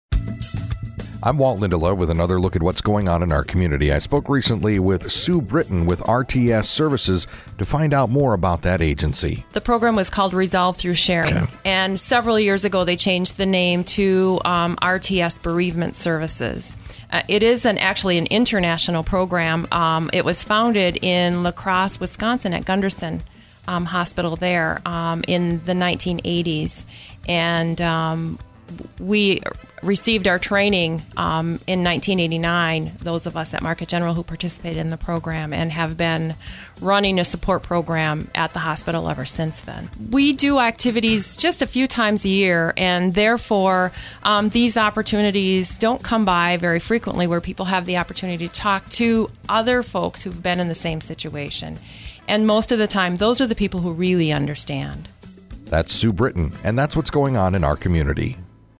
INTERVIEW: MGH Grief and Bereavement services